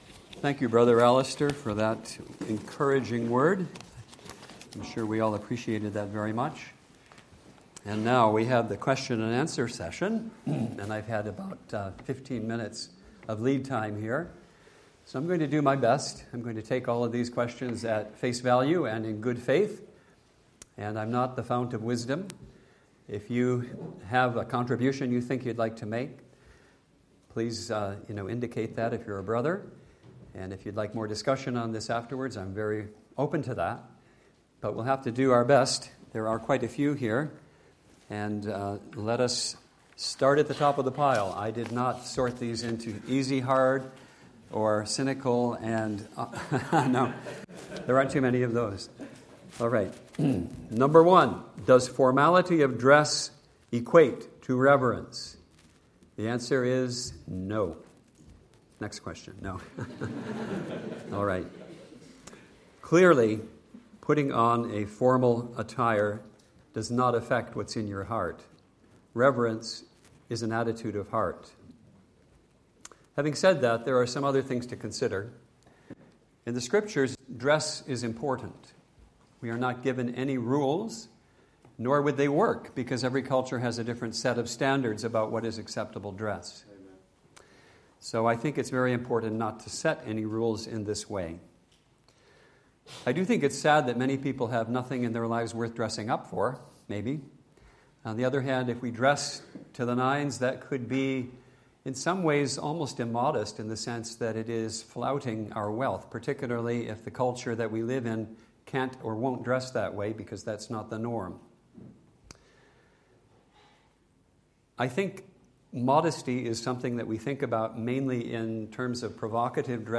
2025 Easter Conference